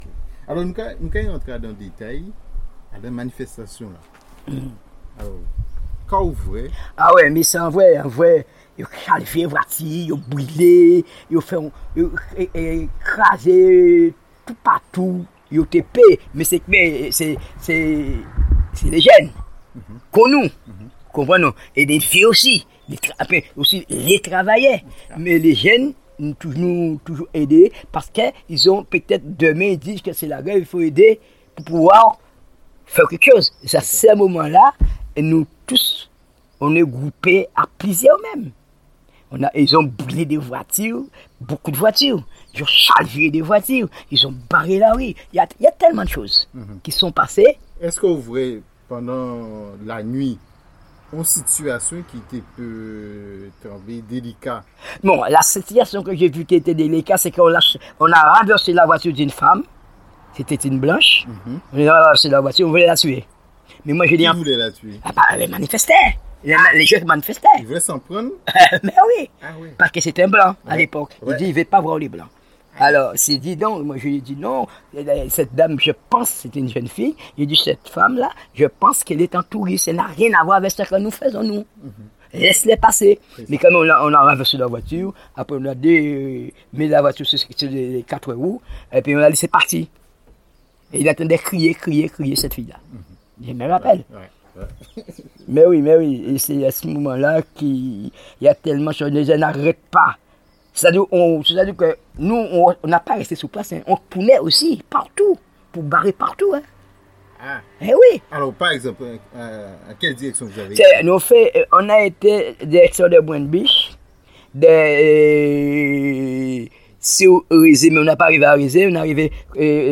Témoignage sur les évènements de mai 1967 à Pointe-à-Pitre.